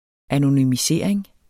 Udtale [ anonymiˈseˀɐ̯eŋ ]